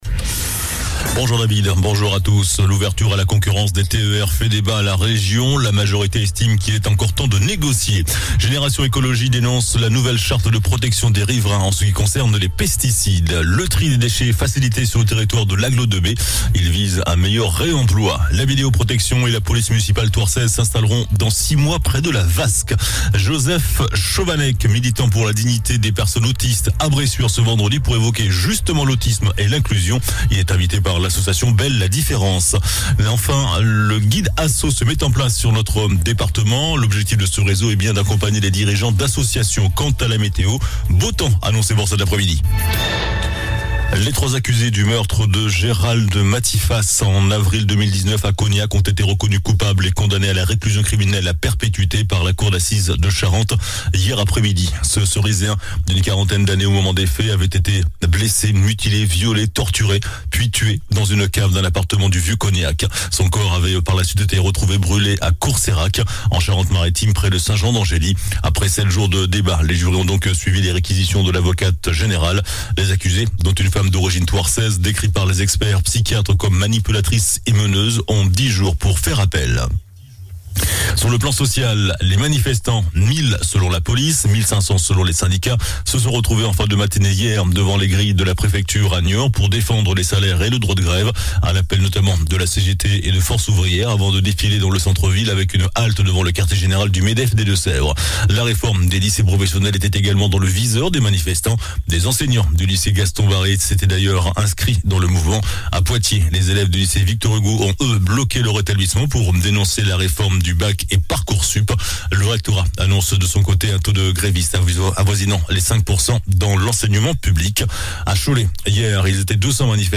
JOURNAL DU MERCRED 19 OCTOBRE ( MIDI )